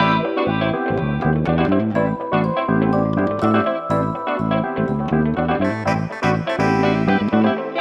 08 Backing PT1.wav